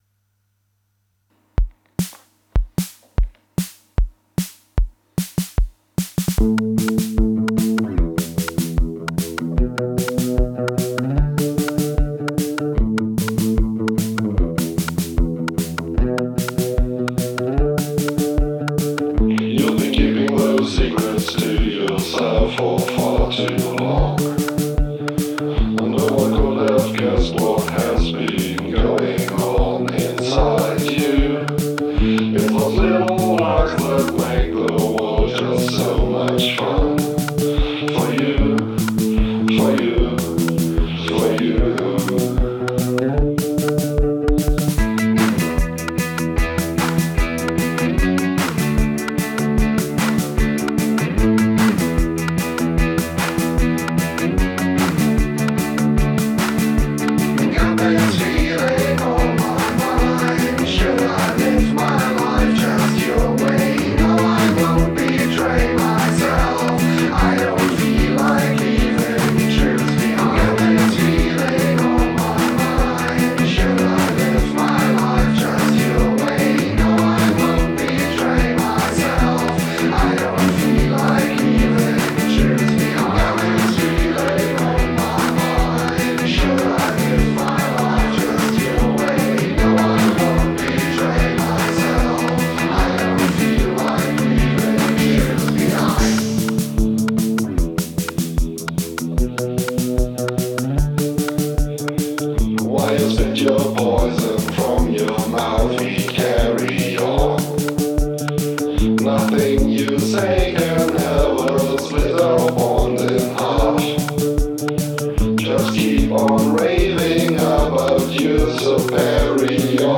Total fusion in one big confusing package!